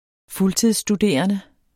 Udtale [ ˈfultiðˀs- ]